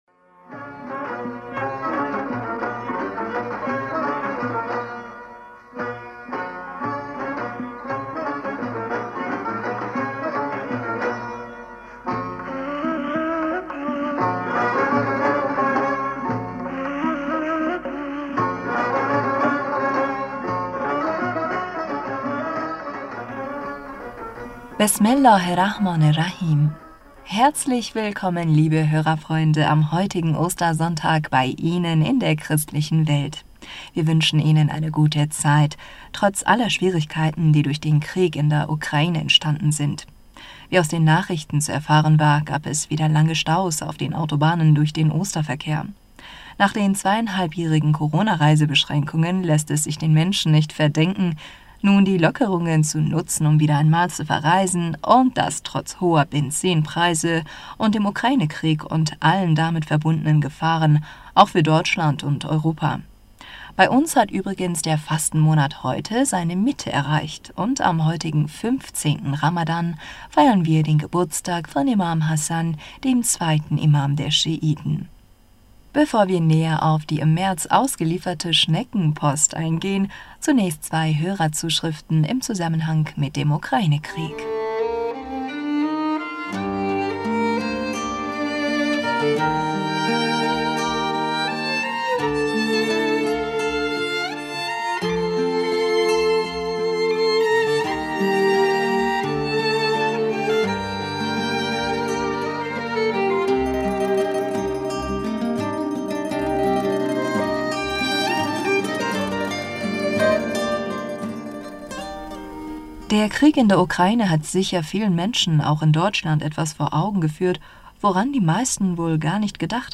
Hörerpostsendung am 17. April 2022